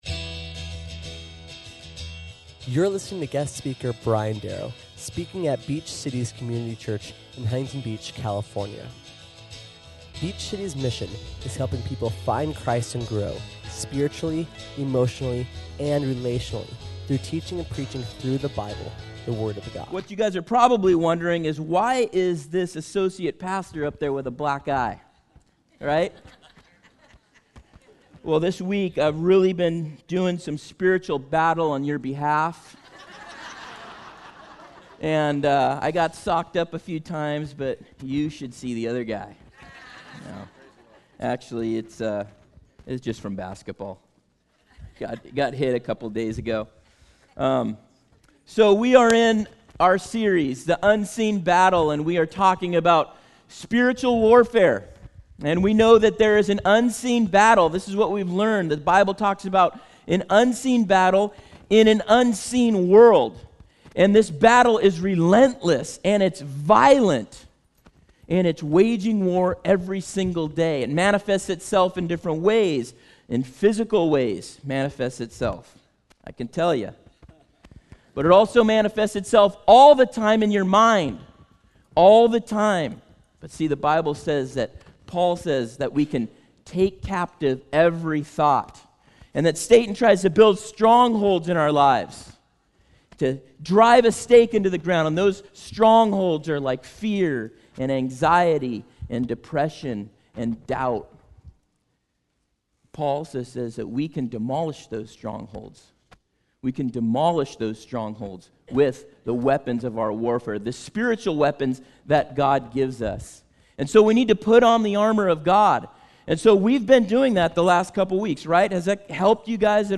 Listen or watch as he teaches us how to use some of the offensive tools that God has given us. SERMON AUDIO: SERMON NOTES: